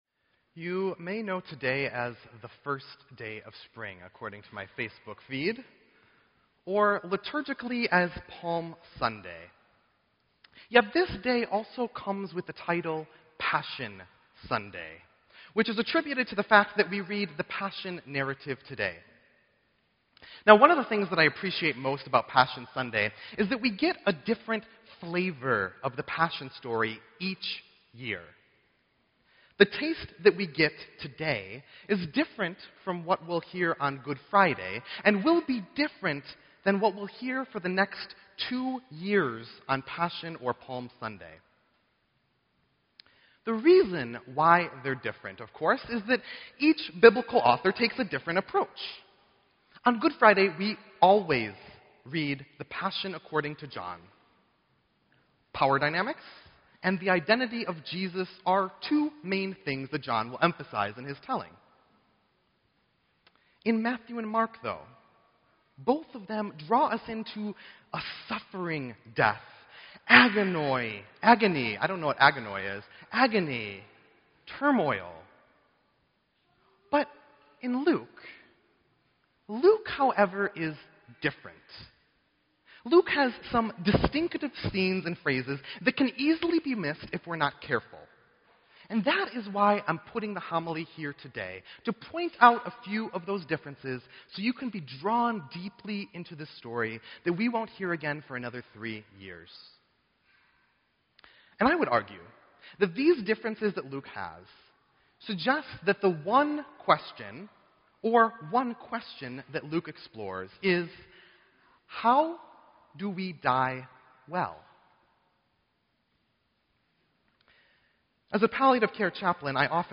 Sermon_3_20_16.mp3